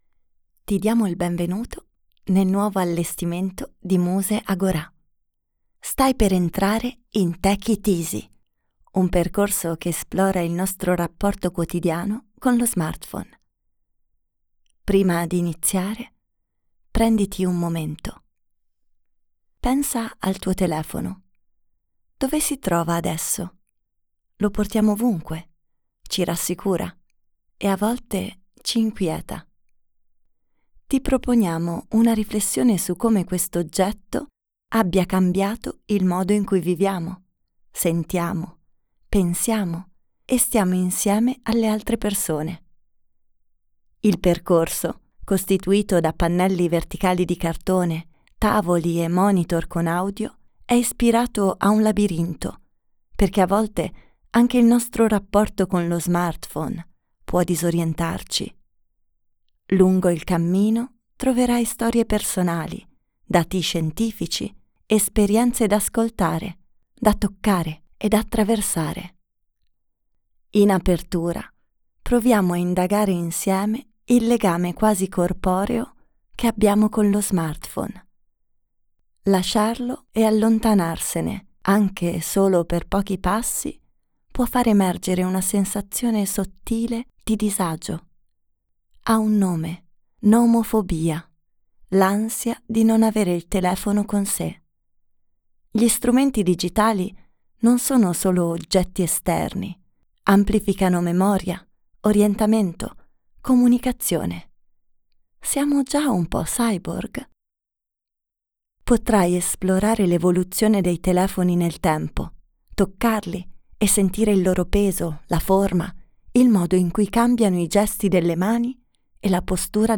Audiodescrizione Tech IT easy! - Vivere con lo smartphone | Muse - Museo delle Scienze di Trento